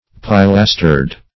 Pilastered \Pi*las"tered\, a. Furnished with pilasters.